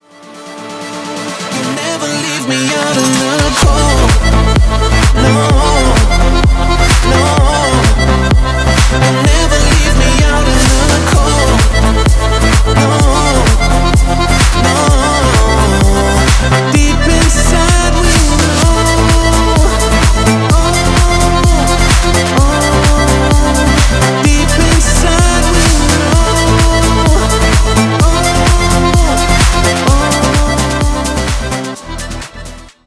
• Качество: 320, Stereo
deep house
качающие
Гармошка